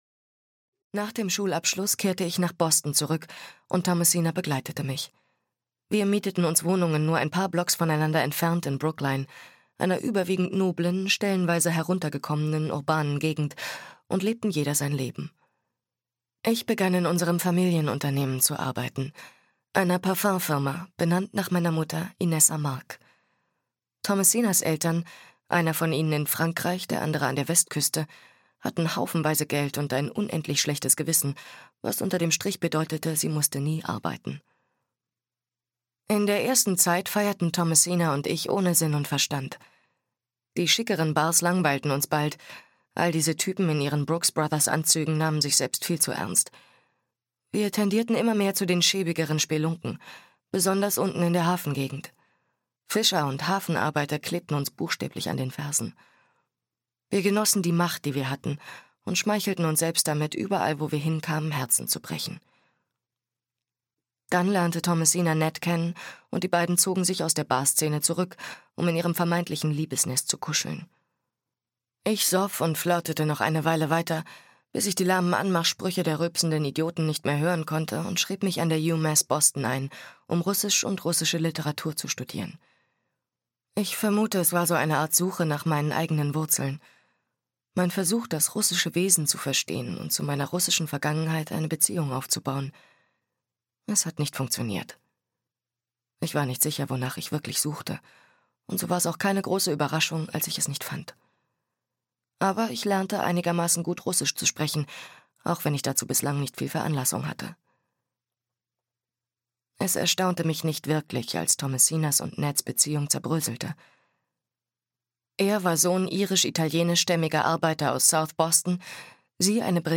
Die Frau, die nie fror - Elisabeth Elo - Hörbuch